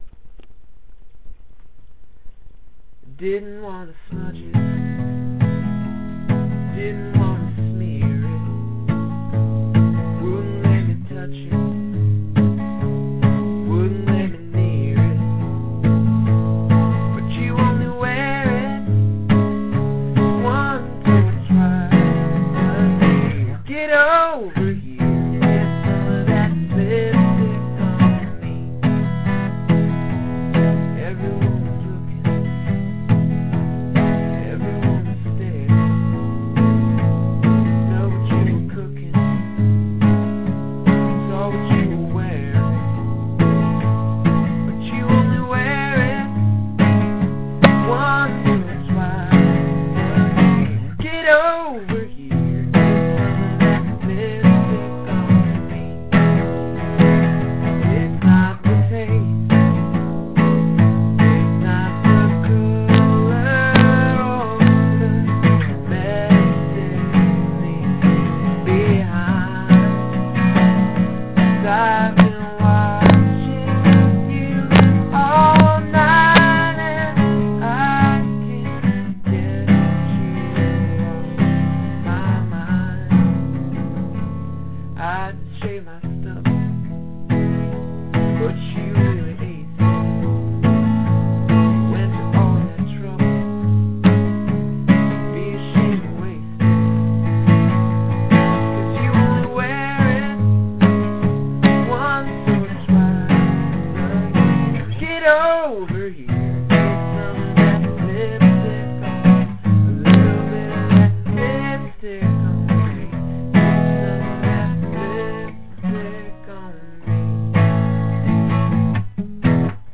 verse: E A c# A
On this initial demo, I messed up and sang "on me" at the very end, where I meant to sing "my dear", which I like better there.
Sometimes it wants to be fun and sometimes it wants to be genuine. I was fighting off a much jazzier version, trying to get a country feel instead, and they sort of canceled each other out somehow or something.